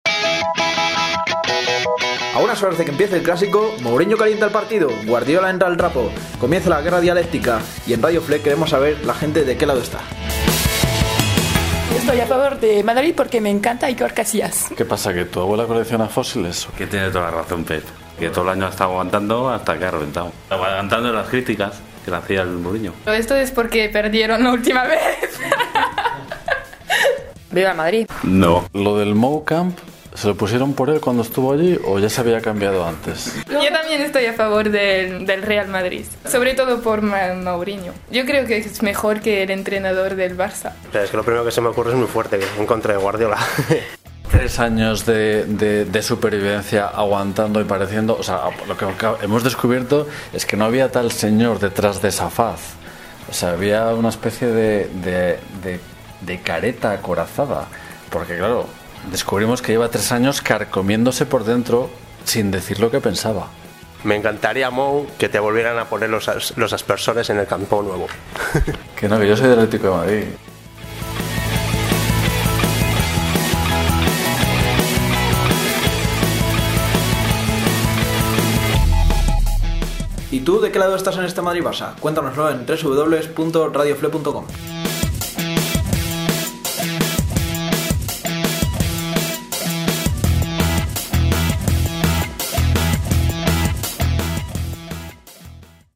En RadioFLE hemos sacado los micrófonos a la calle para preguntar sobre este tema...